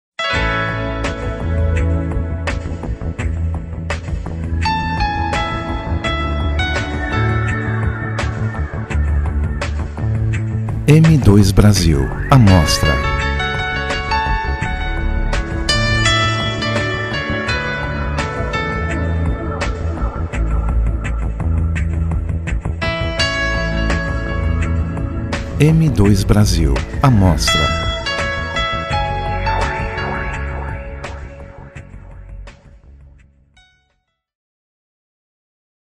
Músicas de Fundo para URA